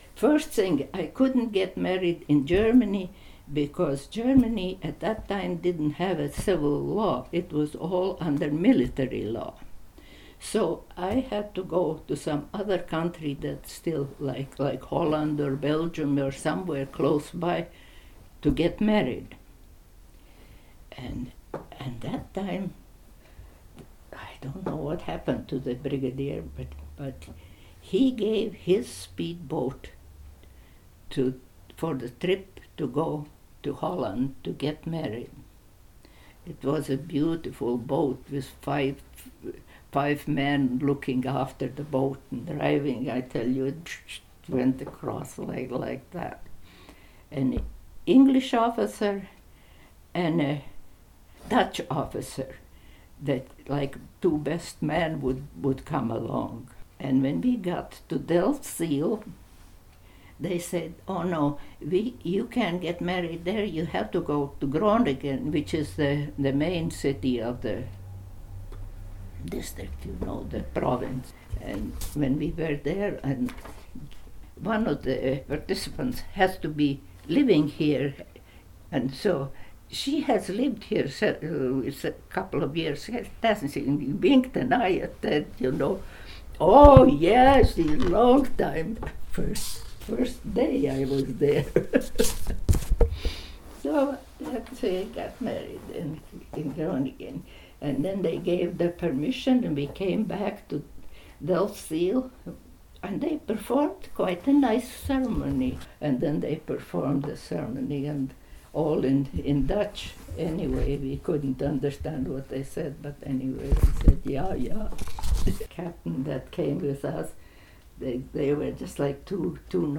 Interviewer